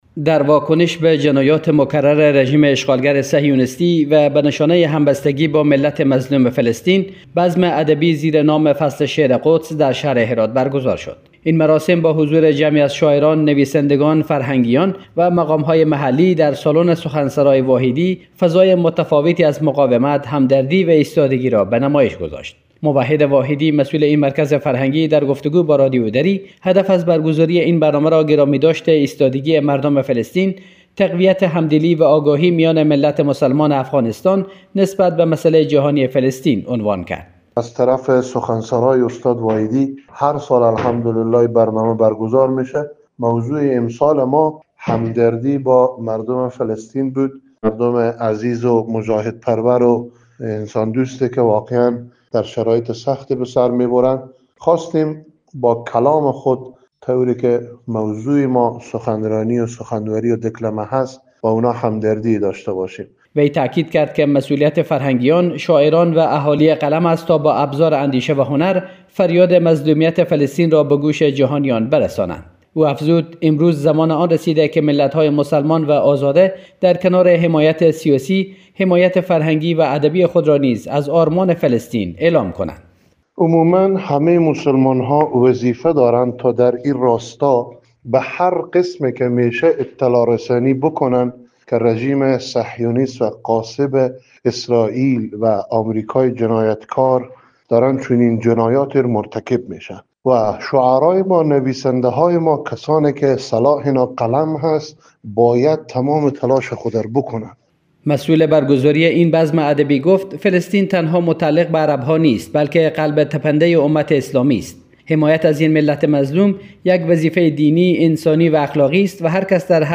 در واکنش به جنایات مکرر رژیم اشغالگر صهیونیستی و به نشانه همبستگی با ملت مظلوم فلسطین، بزم ادبی زیر نام «فصل شعر قدس» در شهر هرات برگزار شد.
در این بزم، جمعی از شاعران نامدار و جوان با خوانش اشعاری حماسی، از مقاومت مردم فلسطین در برابر اشغال و ظلم رژیم صهیونیستی ستایش کرده و پیام همبستگی ملت افغانستان را با ملت فلسطین در قالب شعر به تصویر کشیدند.